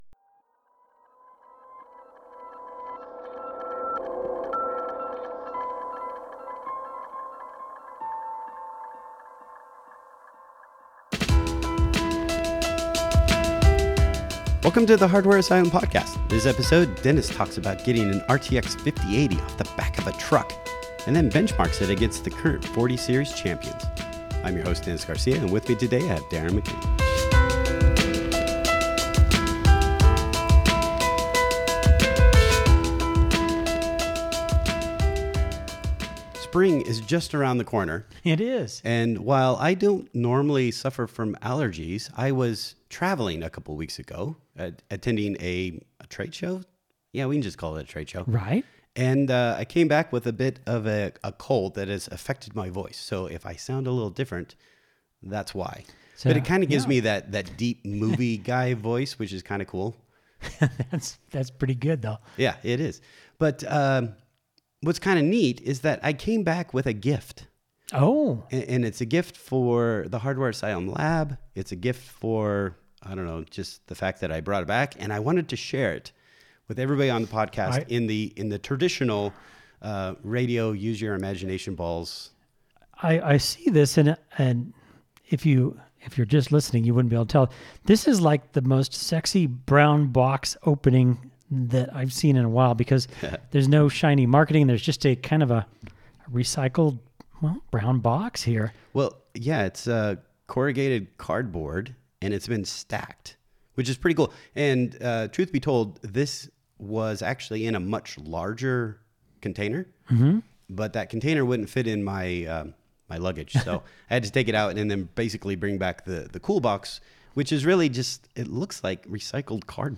Just two guys talking tech